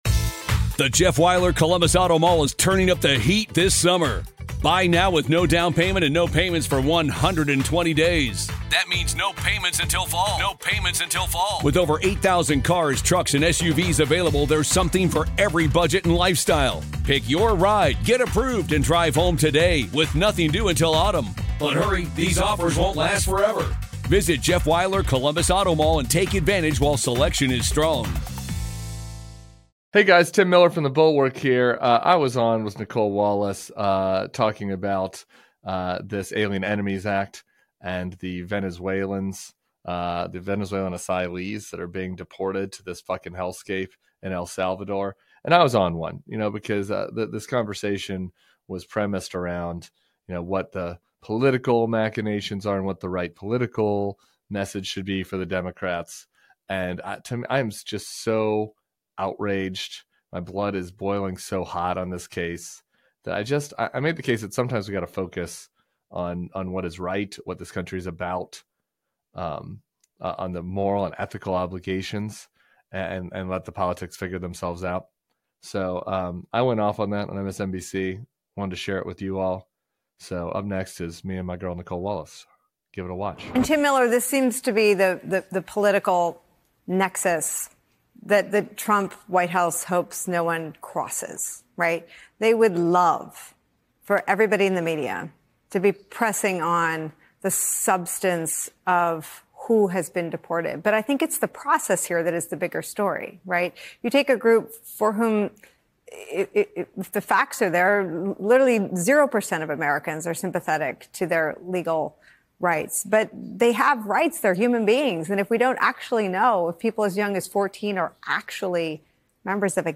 Tim Miller joins Nicolle Wallace on MSNBC's Deadline: White House to discuss the Trump administration's deportation of migrants without due process.